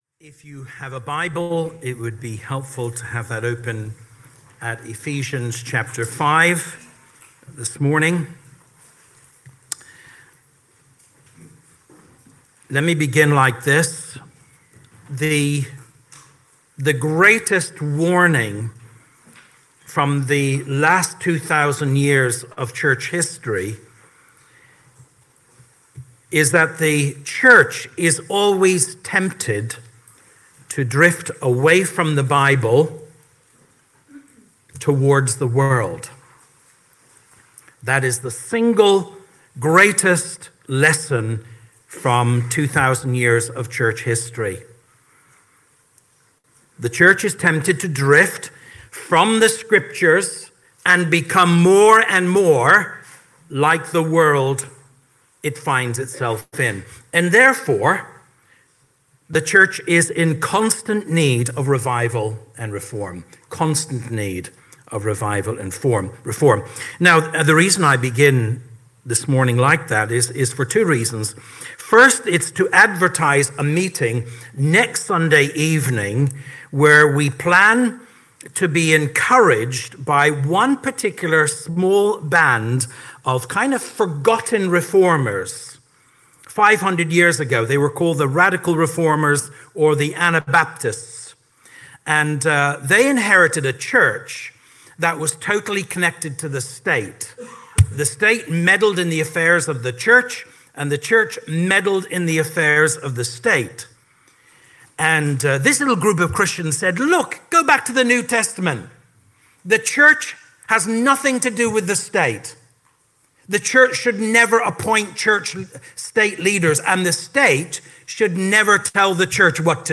12 Jan 25 Sermon Audio.mp3